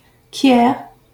Quiers (French pronunciation: [kjɛʁ]